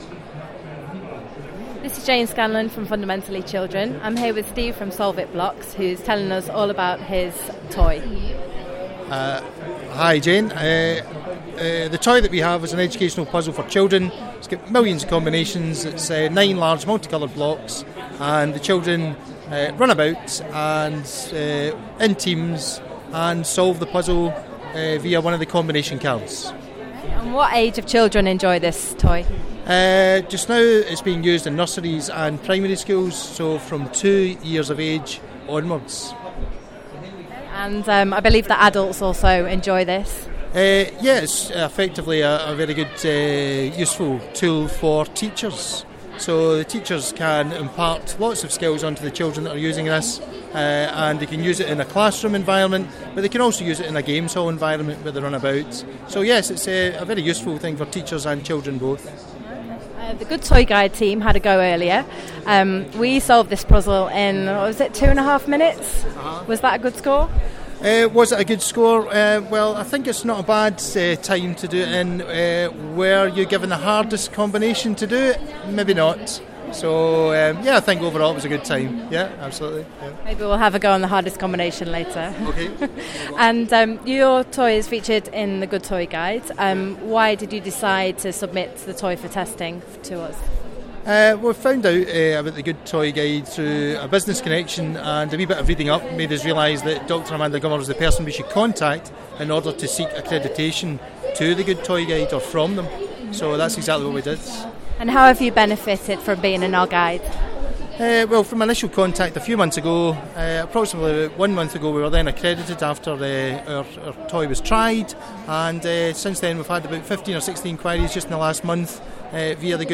Fundamentally Children Interview